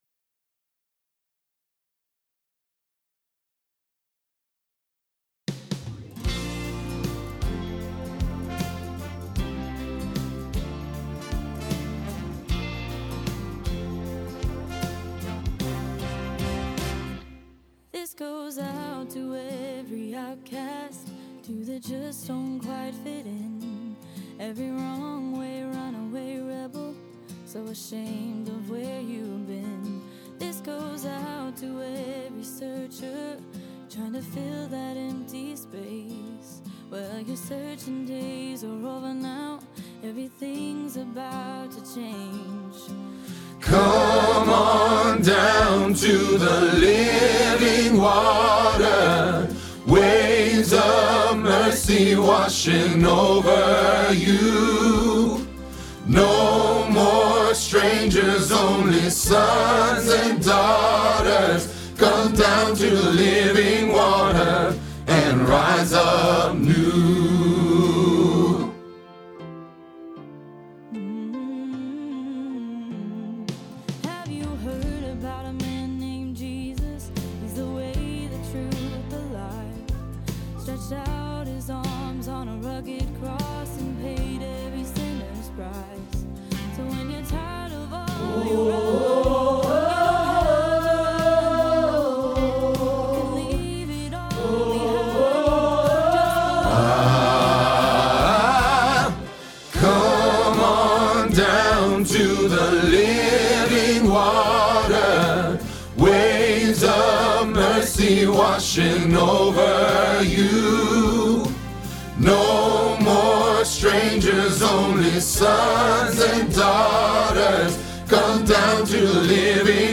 Living Water – Bass – Hilltop Choir
01-Living-Water-Bass-PTX.mp3